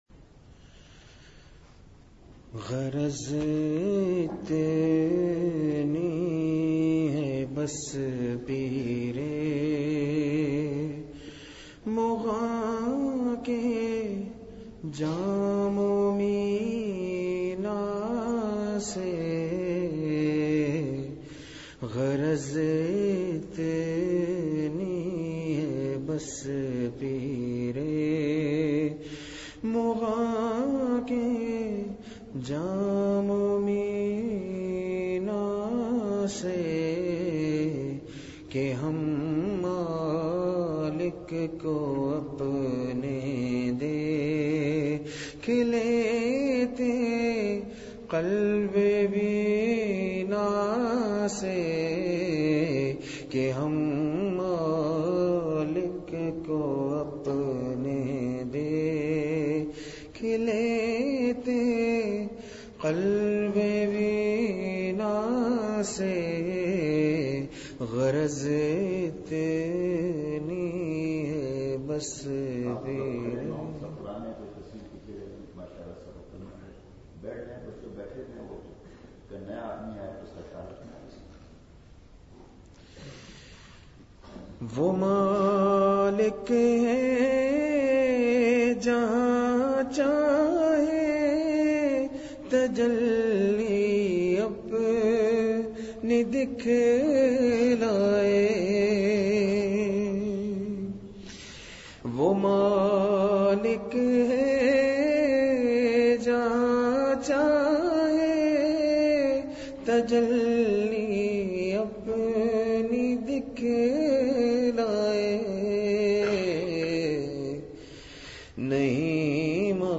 مقام: جامعہ تعلیم القران تختہ بند سوات
مدرسہ سے متصل مسجد میں حضرت والا کا پرنور بیان ہوا۔ *عنوان اللہ تعالیٰ شانہ کی شان جذب۔ والدین کے حقوق۔ تکبر سے بچنے کی نصائح۔ حیاء کی احادیث۔